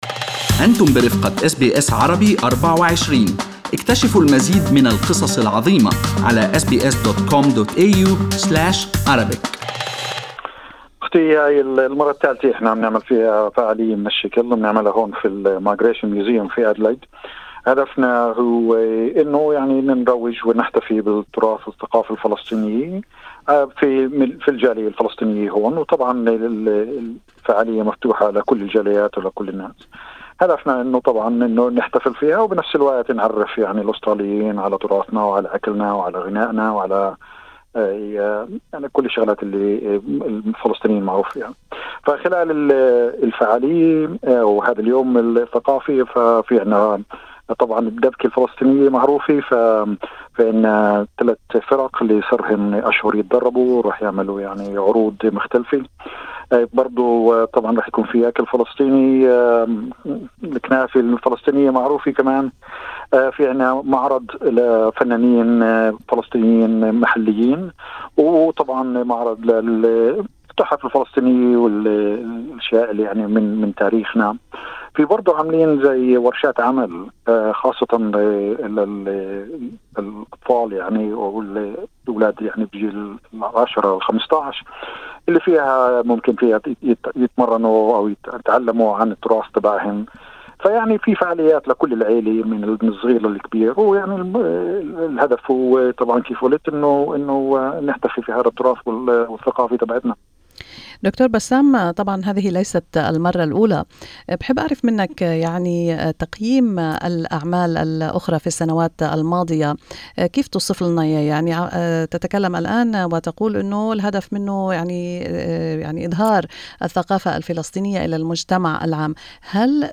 اللقاء الكامل